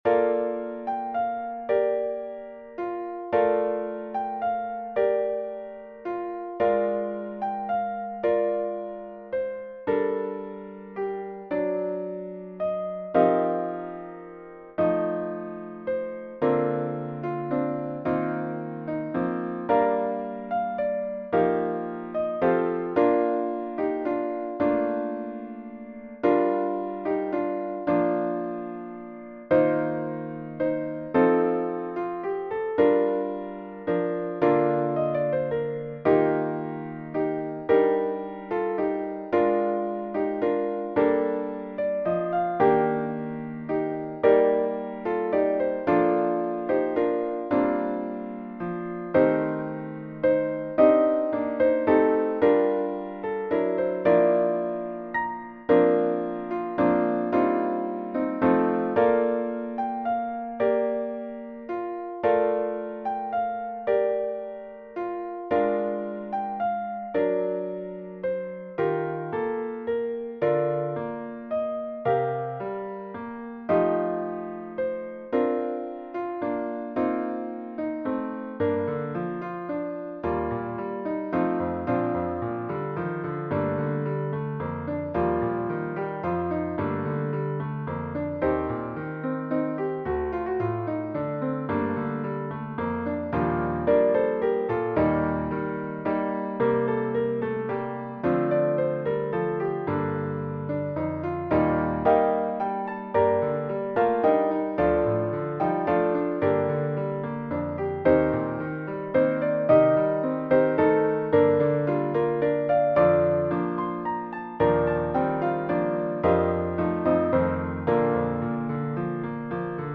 Voicing/Instrumentation: SATB We also have other 118 arrangements of " Silent Night ".
Choir with Congregation together in certain spots